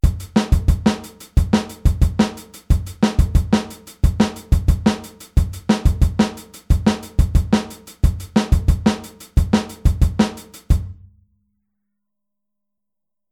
Aufteilung linke und rechte Hand auf HiHat und Snare
Groove23-16tel.mp3